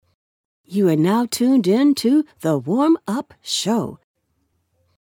Female
Adult (30-50), Older Sound (50+)
Radio / TV Imaging
Podcast-Smooth-Soothing-Clear